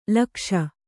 ♪ lakṣa